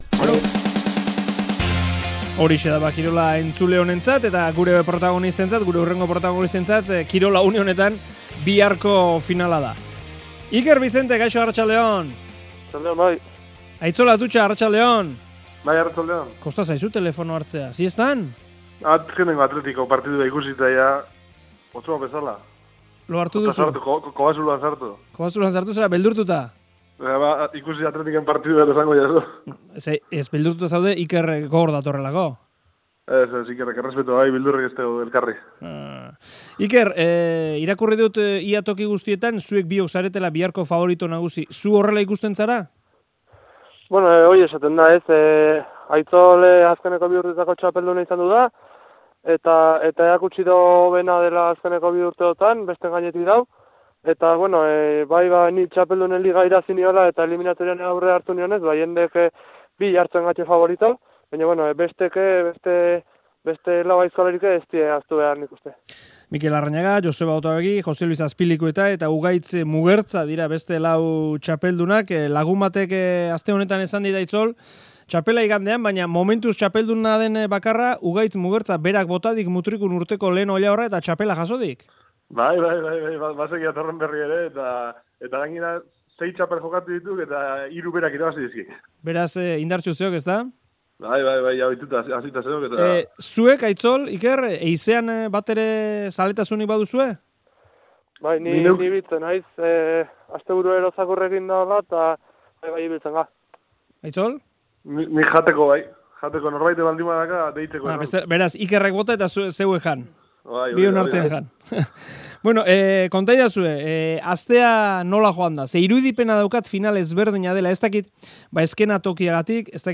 Euskal Herriko aizkolari txapelketaren bezperan aurrez aurre izan ditugu bi txapeldunak gurean.